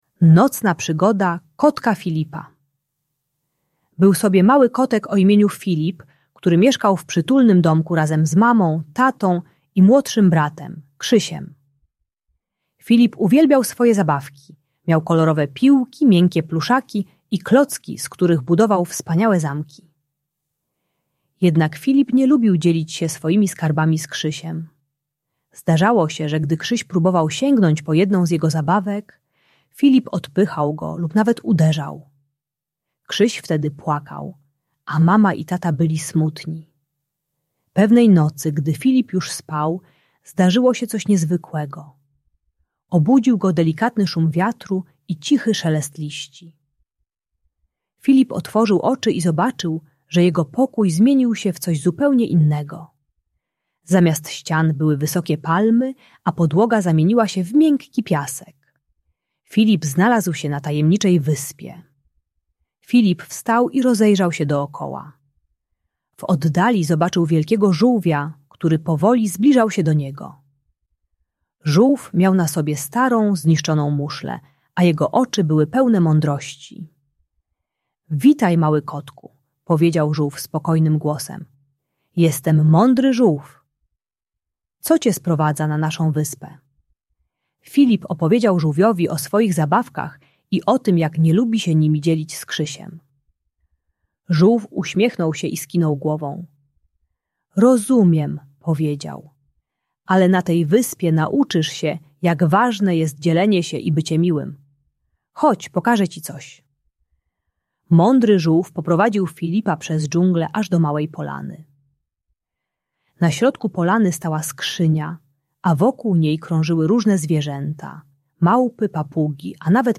Nocna Przygoda Kotka Filipa - Rodzeństwo | Audiobajka